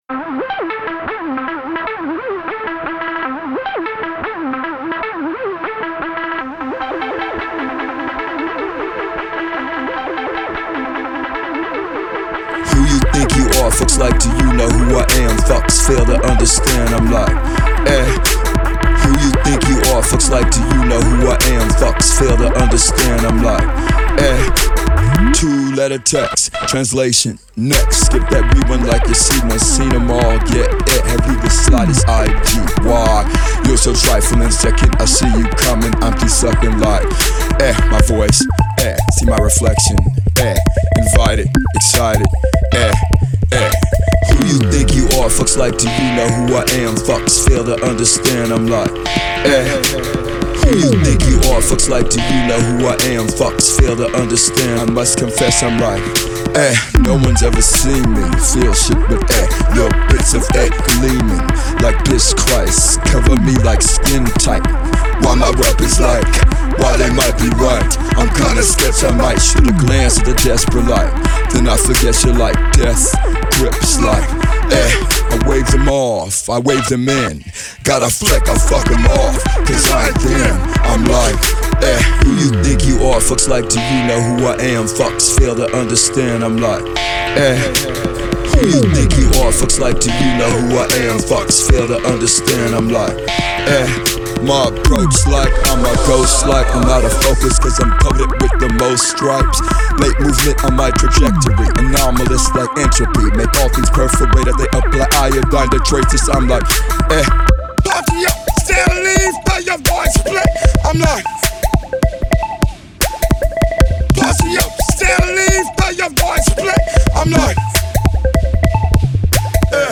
experimental sounds